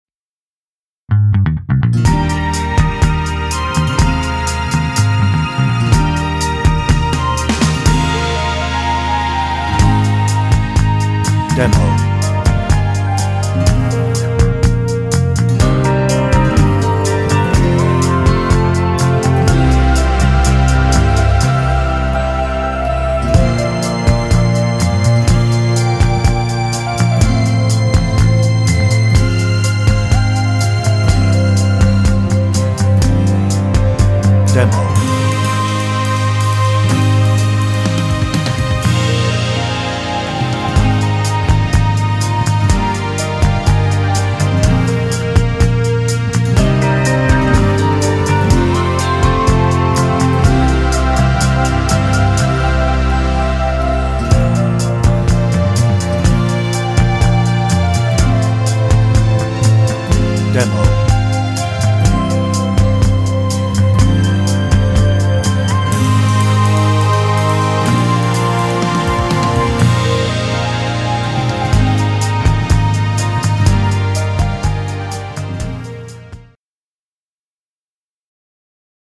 Instrumental (No Melody)